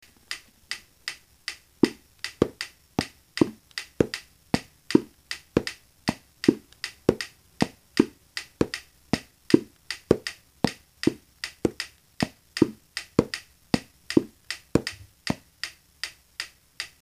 If you can't read music, you may find these audio clips useful. a metronome in the background keeps the basic beat to help you get oriented:
Rhythm 5 being played by beating with a pen on the lids of three different sizes of empty plastic containers.